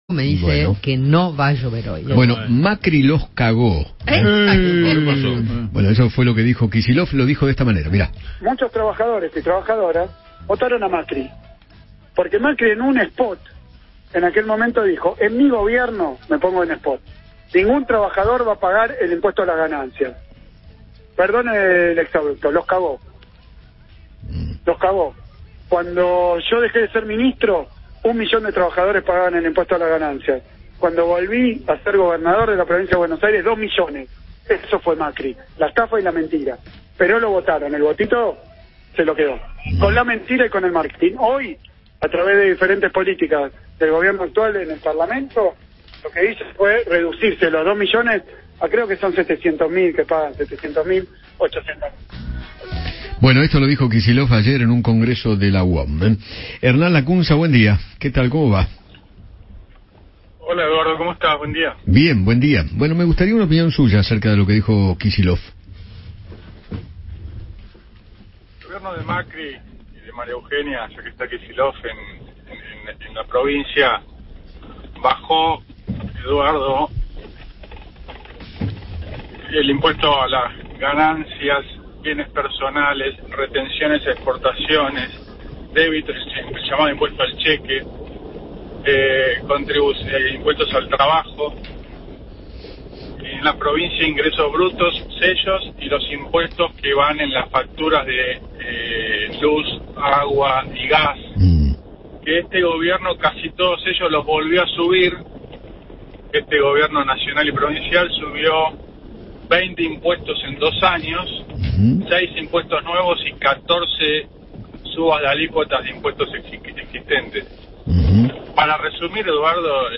Hernán Lacunza, ex ministro de Economía, dialogó con Eduardo Feinmann sobre las declaraciones de Axel Kicillof durante un acto de la UOM, donde aseguró que “Macri cagó a los argentinos”.